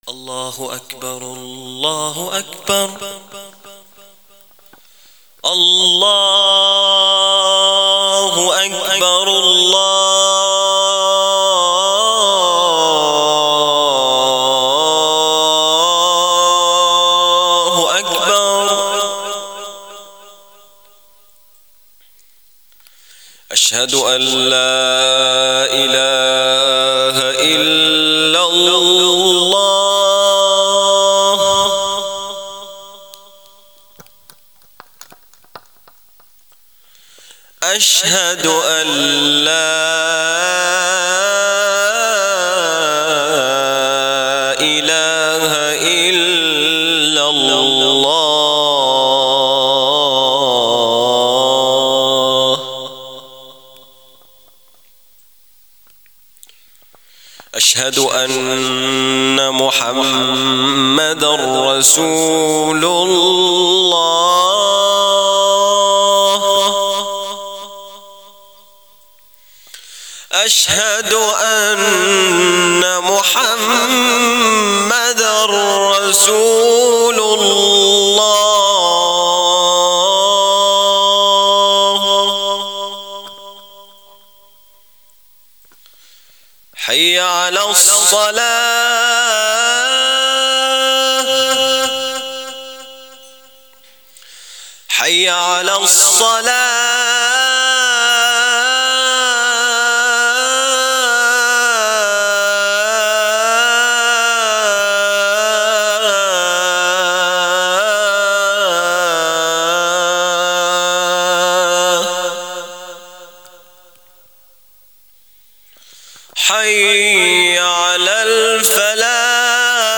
أناشيد ونغمات
الأذان